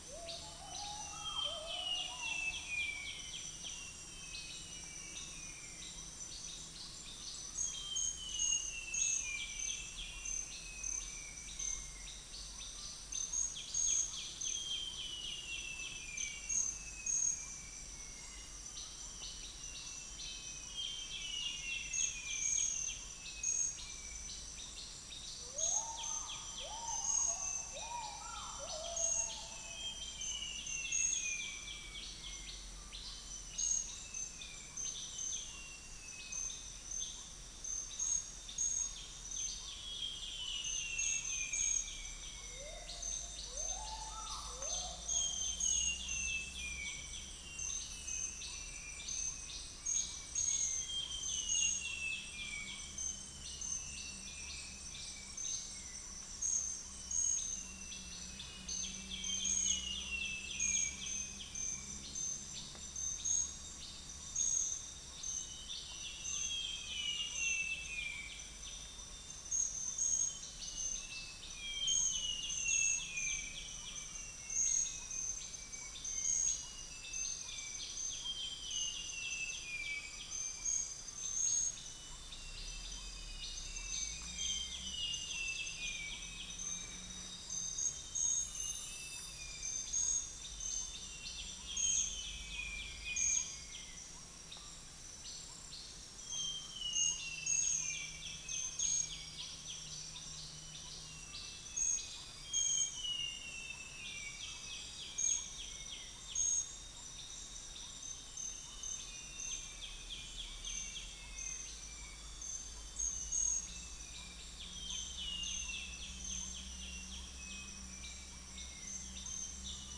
Upland plots dry season 2013
Cuculus micropterus
Gracula religiosa
Malacopteron magnirostre
Irena puella